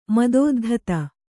♪ madōddhata